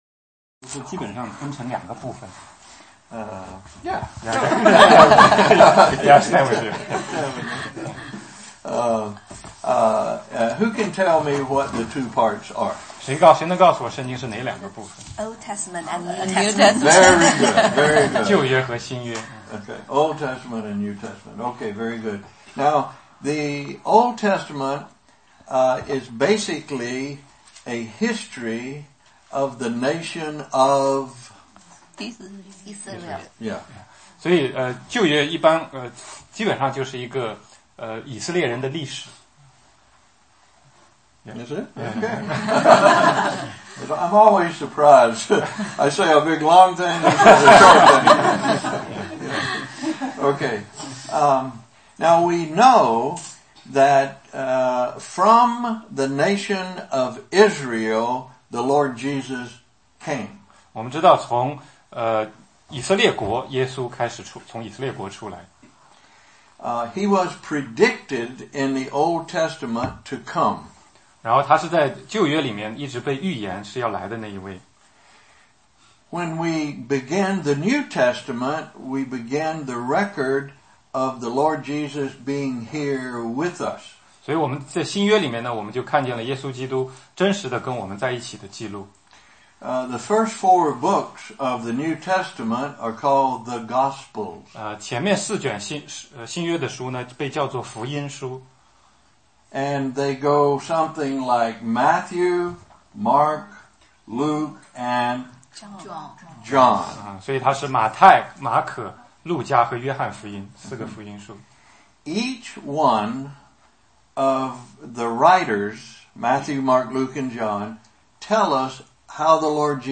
16街讲道录音 - 历代志下20 1-11